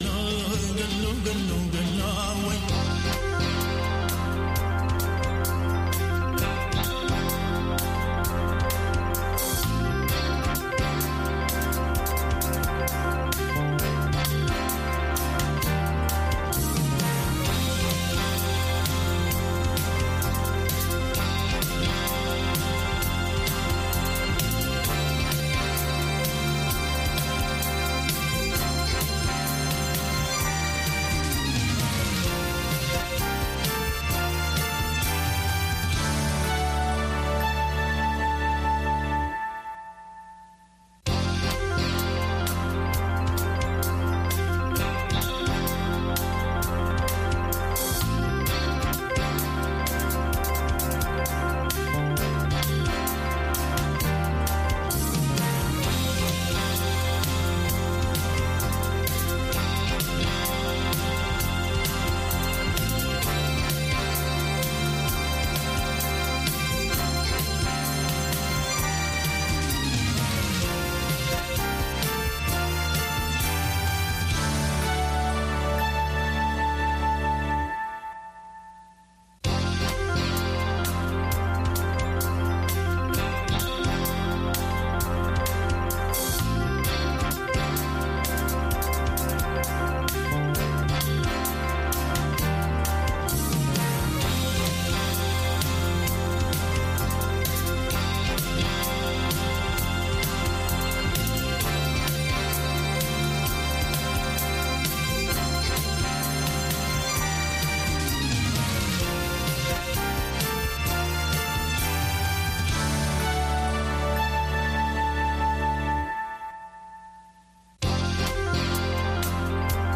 هەواڵە جیهانییەکان 2